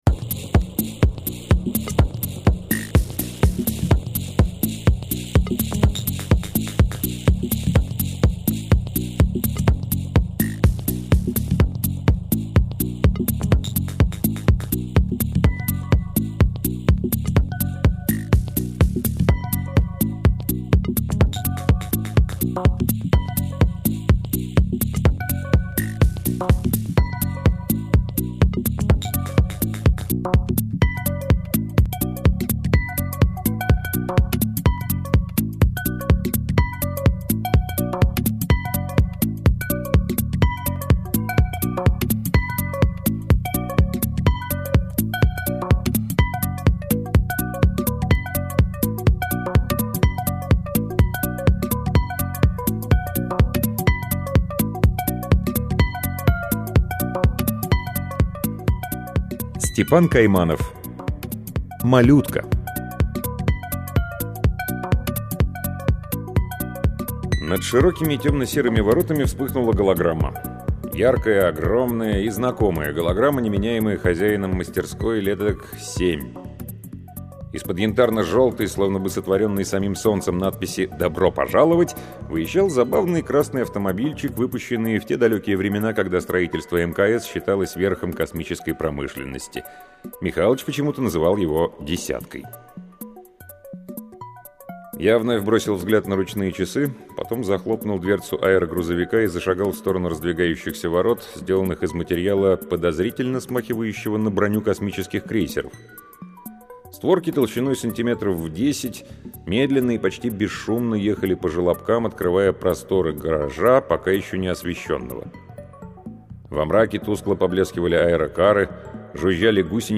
Аудиокнига Степан Кайманов — Малютка